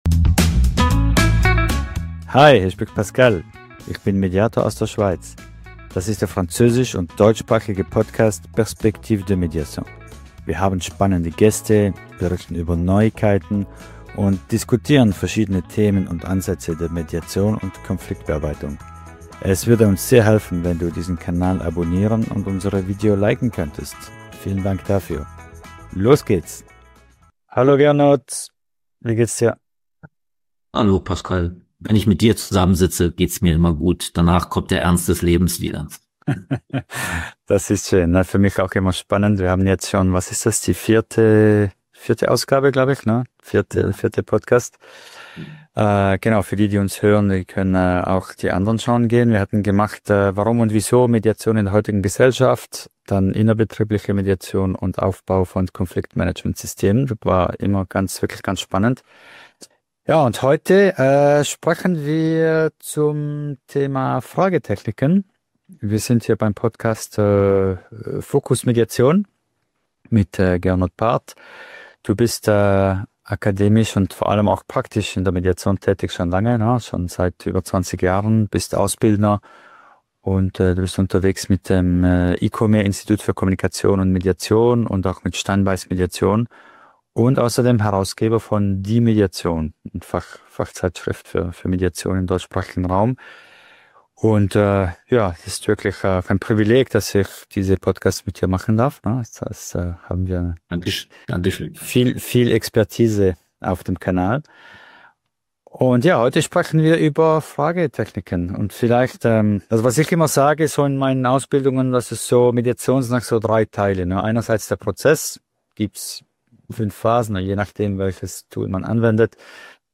🛠 Ein inspirierender Austausch mit Tiefgang und einem Hauch Humor!